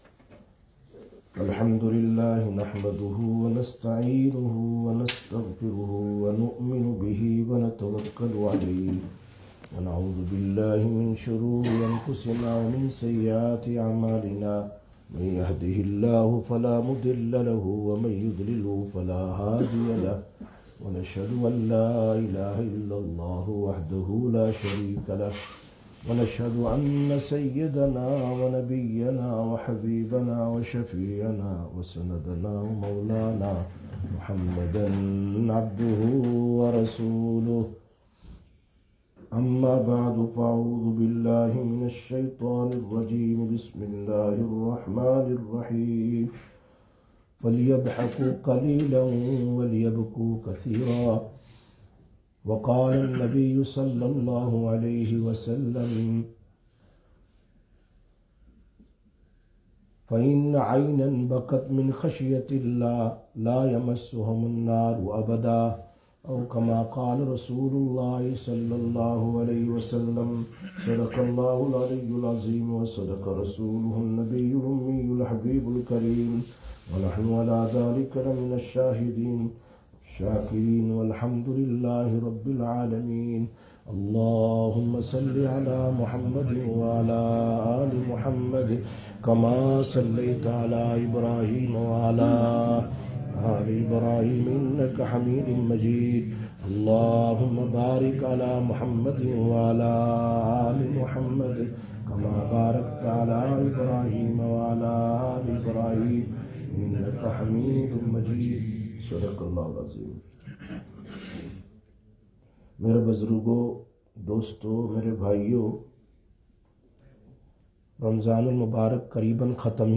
07/05/2021 Jumma Bayan, Masjid Quba